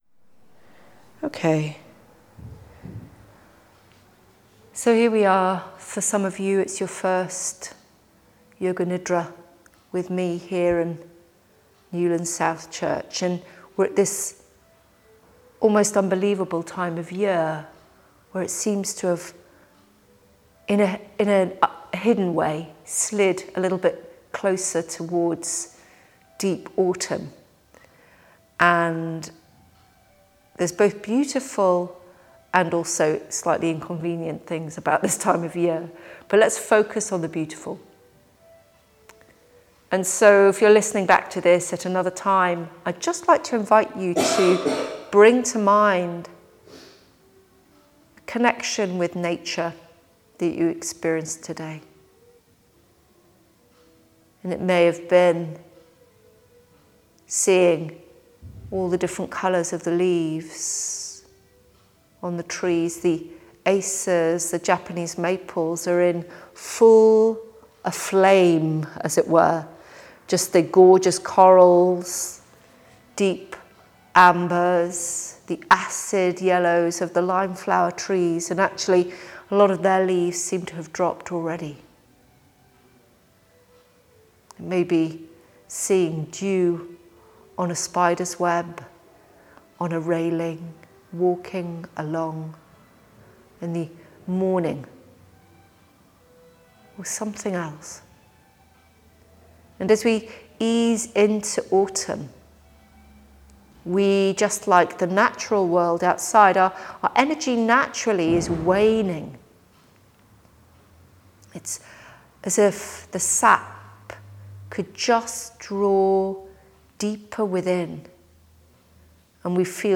Early-Autumn-Prenatal-nidra.mp3